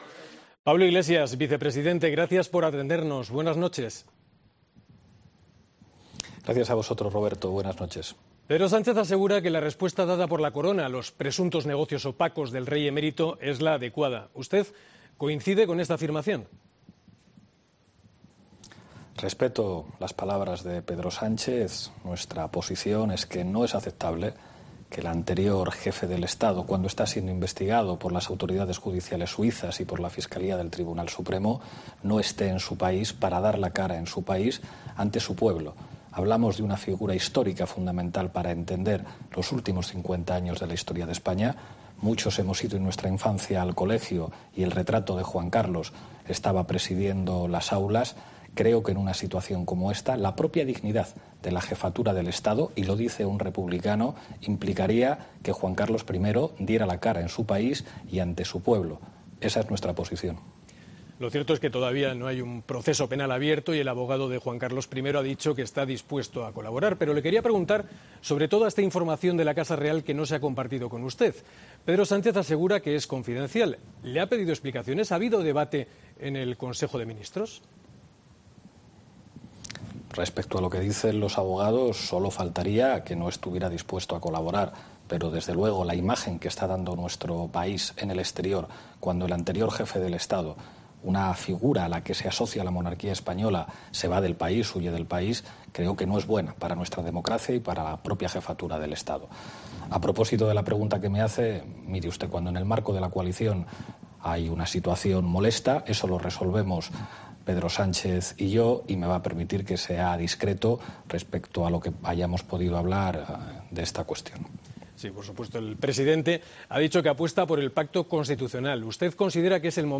El líder de Podemos ha ofrecido una breve entrevista en televisión en la que ha valorado las discrepancias en el seno de la coalición de Gobierno